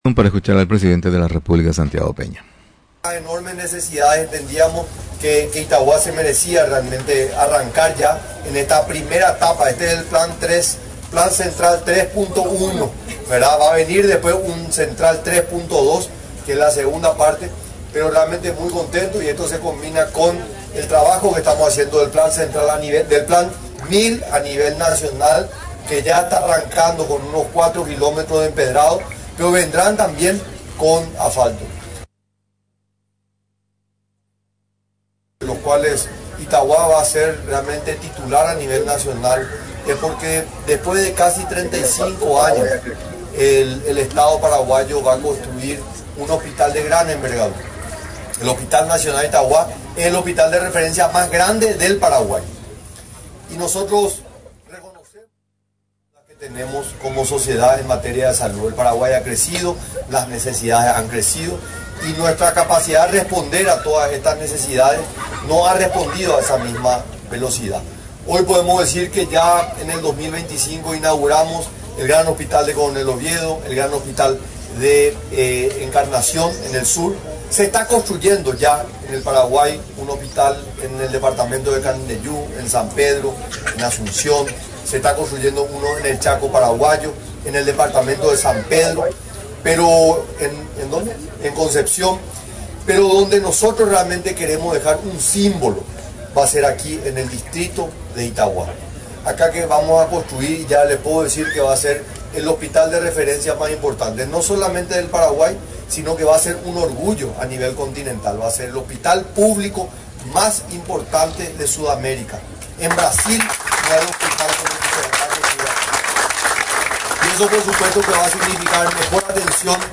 En un acto que contó con la presencia del presidente de la República, Santiago Peña y el vicepresidente de la República, Pedro Alliana, el Gobierno a través del Ministerio de Urbanismo, Vivienda y Hábitat, inauguró unas 27 casas nuevas en el distrito de Itauguá, departamento Central.